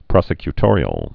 (prŏsĭ-ky-tôrē-əl)